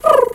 pgs/Assets/Audio/Animal_Impersonations/pigeon_2_emote_07.wav
pigeon_2_emote_07.wav